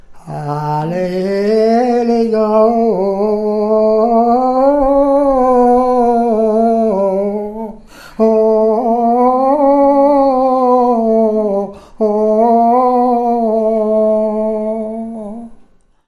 Appels de labour, tiaulements, dariolage, teurlodage, pibolage
couplets vocalisés
chant pour mener les boeufs constitué d'onomatopées, interjections et de noms de boeufs
Pièce musicale inédite